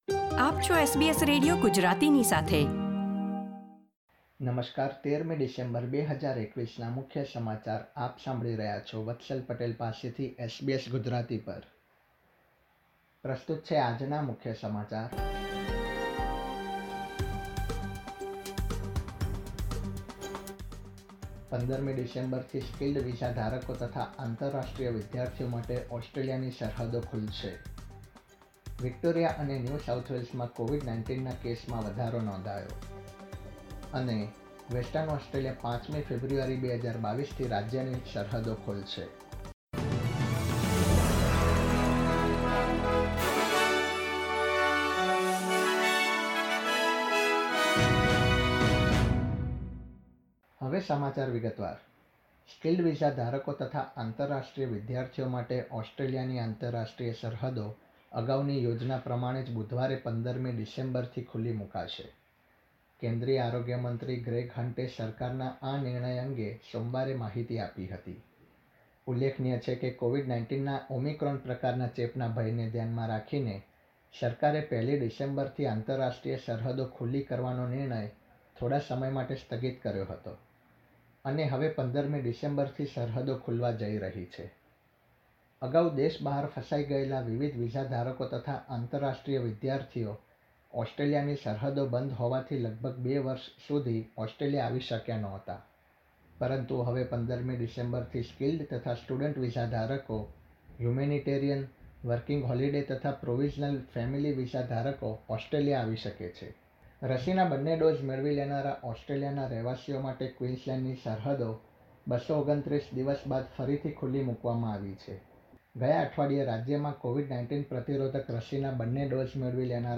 SBS Gujarati News Bulletin 13 December 2021